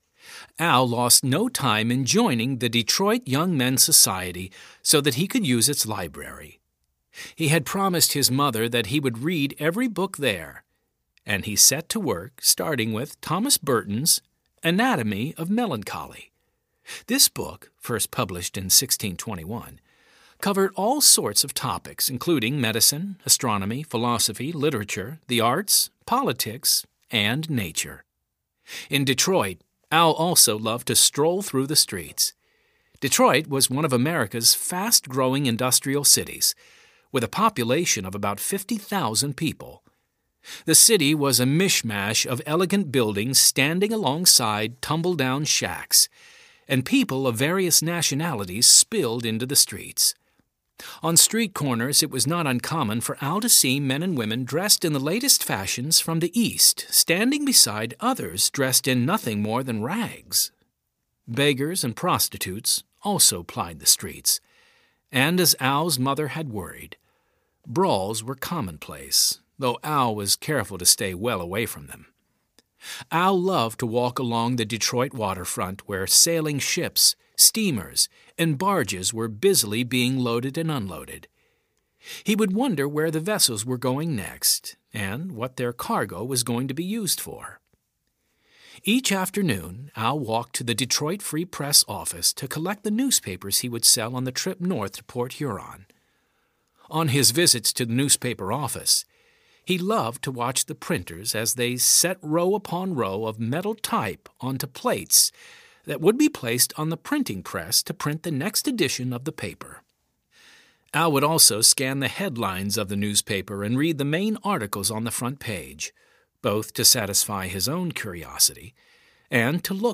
Thomas Edison (Heroes of History Series) Audiobook
The stories of Heroes of History are told in an engaging narrative format, where related history, geography, government, and science topics come to life and make a lasting impression.
5.18 Hrs. – Unabridged